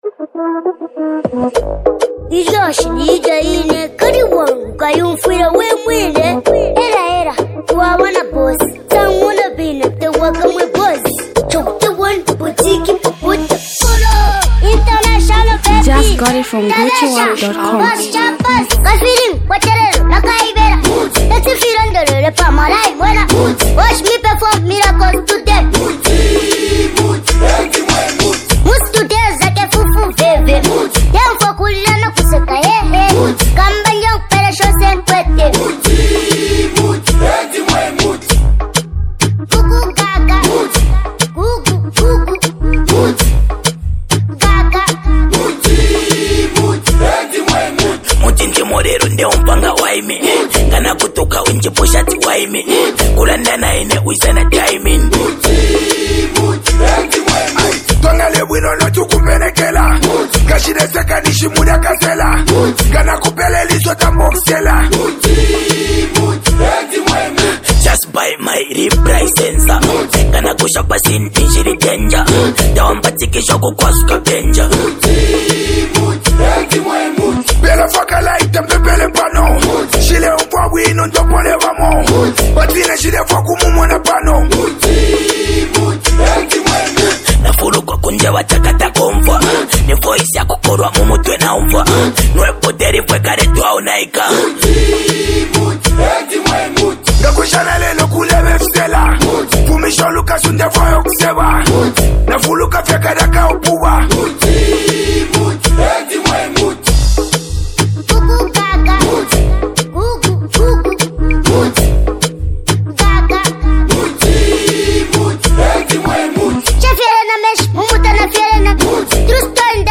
is another heartfelt and mix lyrical sound